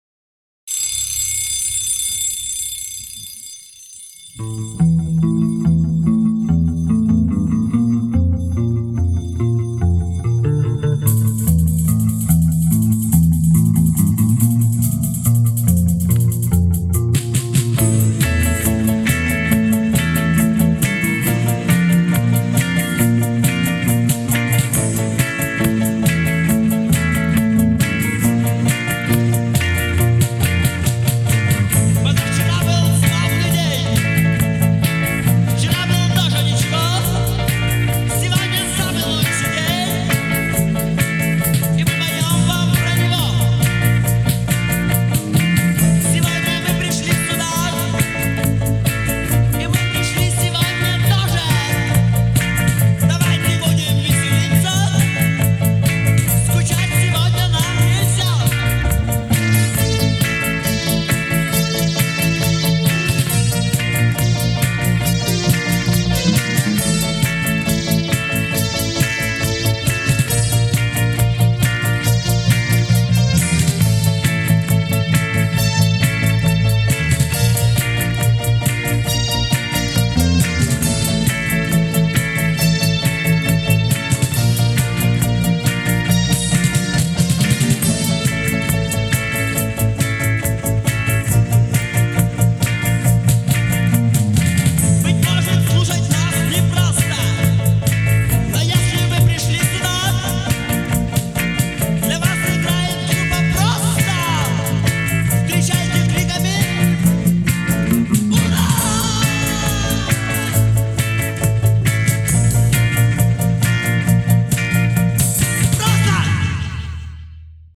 Альбом записан летом 1991 года в Хабаровском Горводоканале
клавишные
барабаны
гитара, вокал
губная гармошка, тамбурин